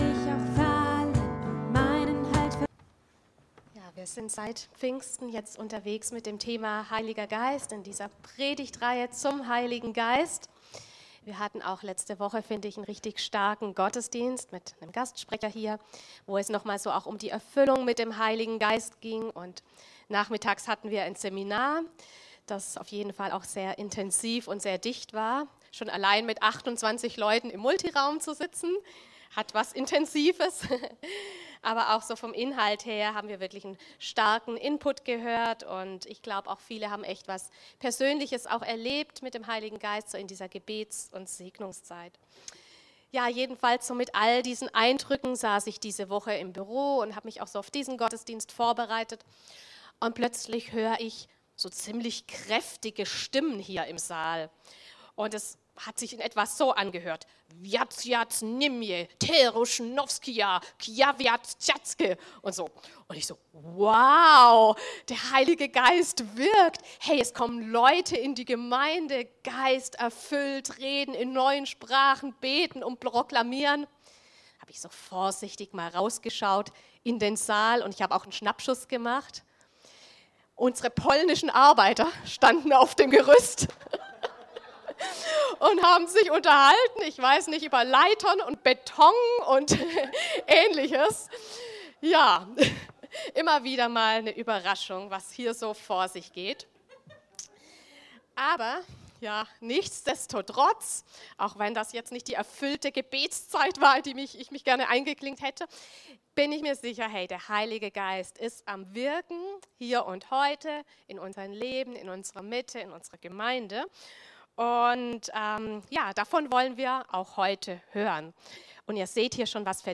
Eine Predigt